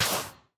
Minecraft Version Minecraft Version 1.21.5 Latest Release | Latest Snapshot 1.21.5 / assets / minecraft / sounds / block / soul_sand / step1.ogg Compare With Compare With Latest Release | Latest Snapshot
step1.ogg